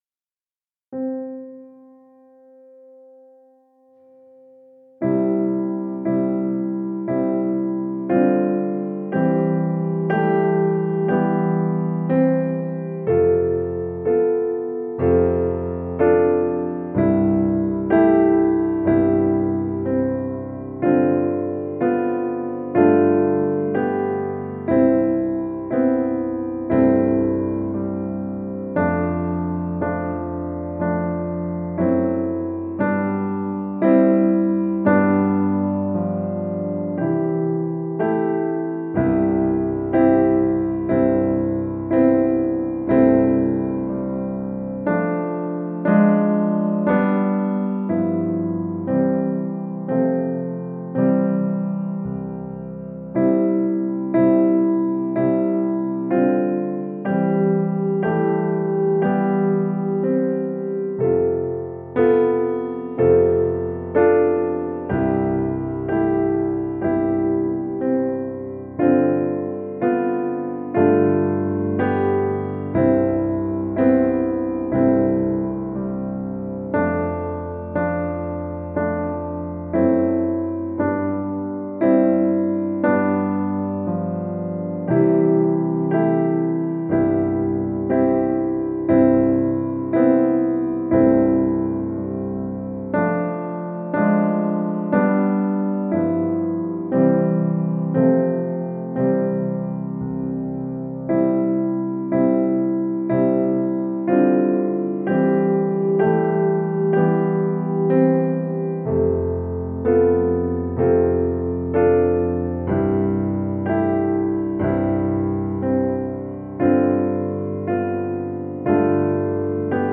„Dicsérd lelkem az Urat” dallamára, 7. szám
Ritmus: 7-1-7-1